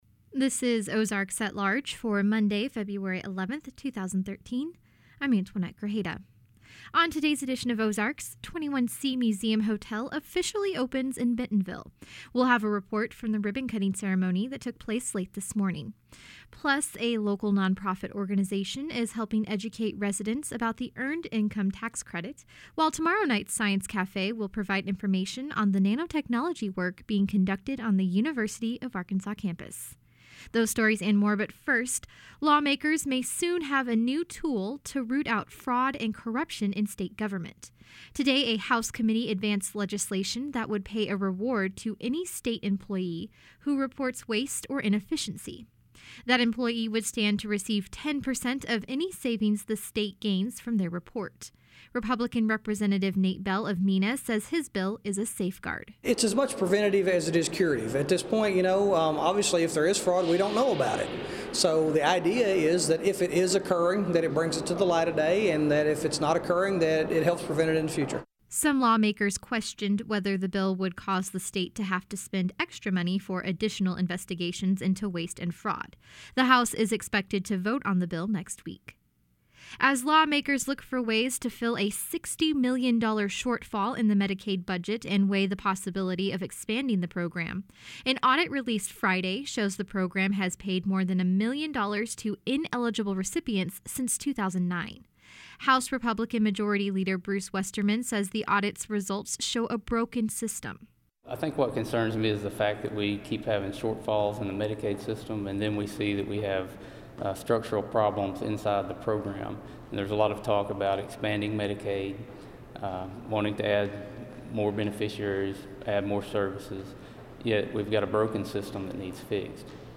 We have a report from the ribbon cutting ceremony that took place late this morning. Plus, local organizations are helping education residents about the Earned Income Tax Credit, while tomorrow night's Science Cafe will provide information on the nanotechnology work being conducted on the University of Arkansas campus.